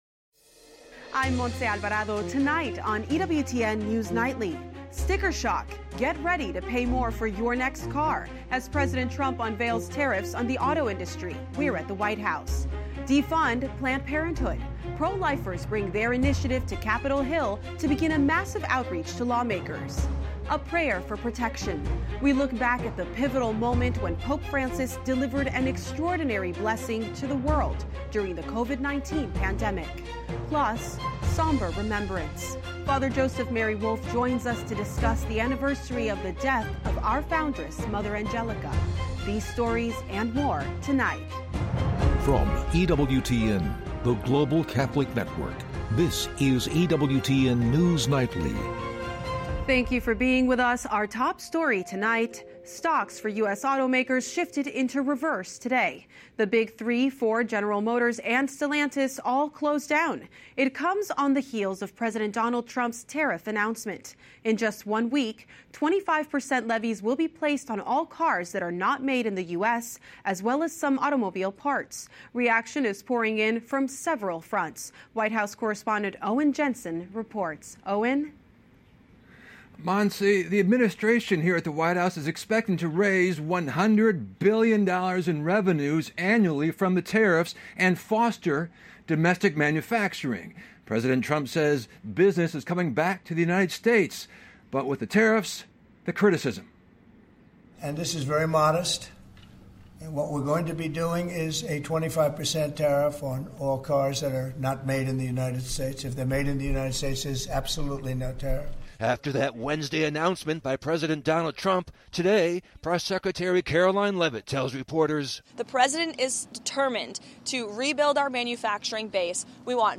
EWTN News Nightly is our daily news and analysis program presenting breaking Catholic News worldwide, top stories, and daily reports from the White House, Capitol Hill, and Rome.